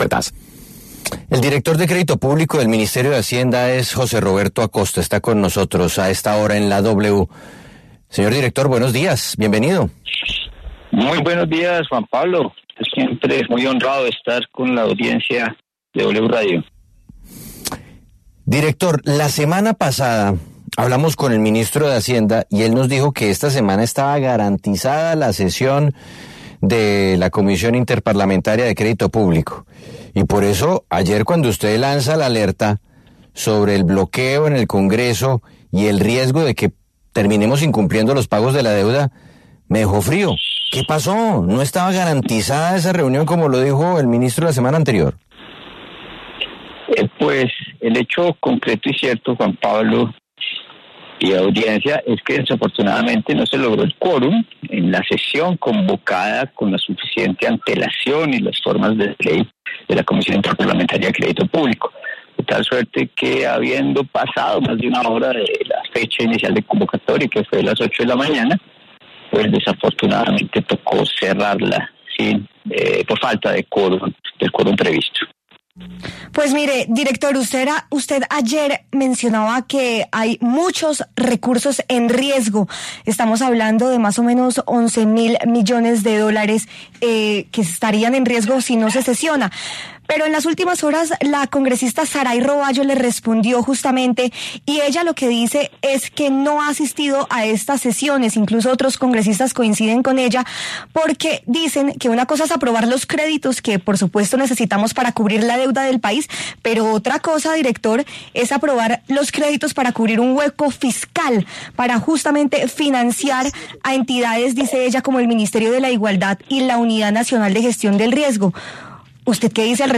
El director de Crédito Público del Ministerio de Hacienda, José Roberto Acosta, se pronunció en La W sobre el riesgo de incumplir los pagos de deuda externa.